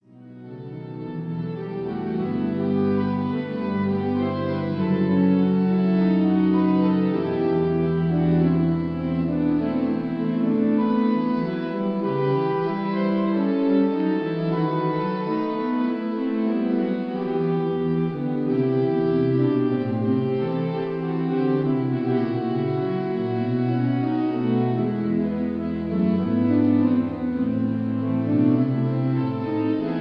Organ of Église Sainte-Aurélie
Strasbourg